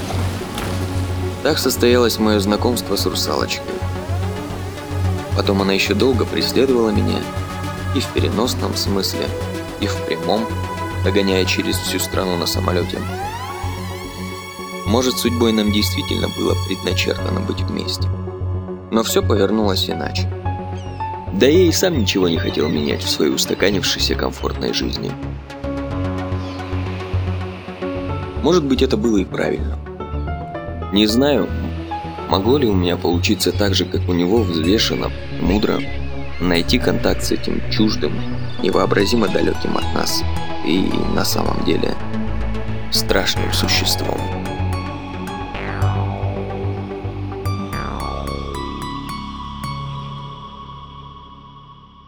Техно-опера